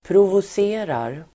Uttal: [provos'e:rar]